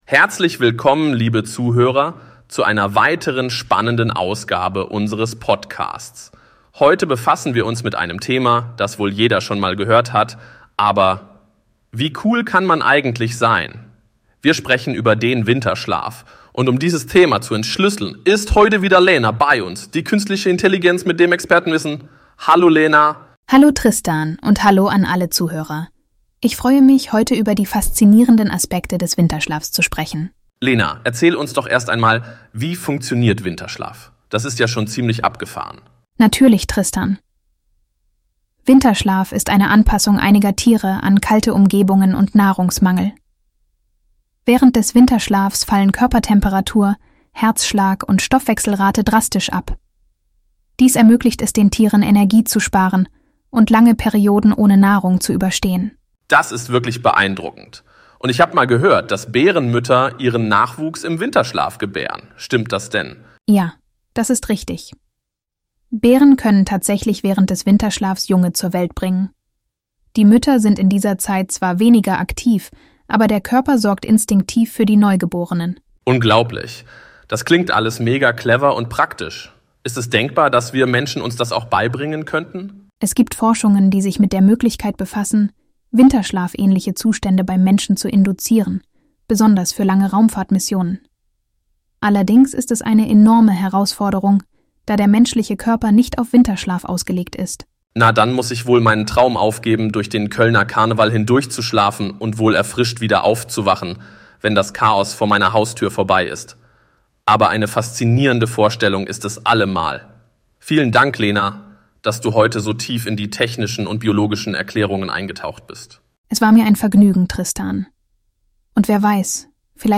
Wir sprechen über den Winterschlaf und dieses Thema zu entschlüsseln, ist heute wieder Lena bei uns, die künstliche Intelligenz mit dem Expertenwissen.